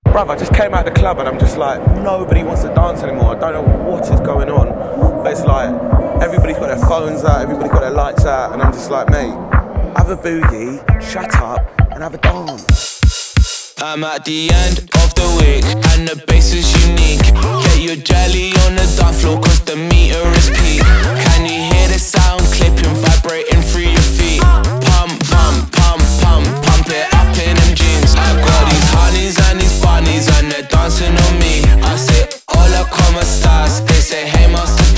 Модульные синтезаторы и глитчи трека
Electronic
Жанр: Электроника